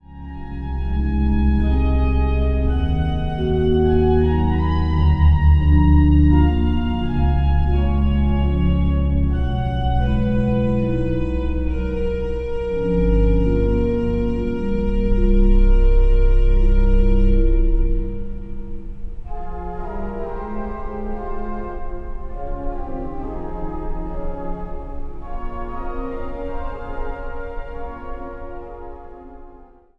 Instrumentaal | Orgel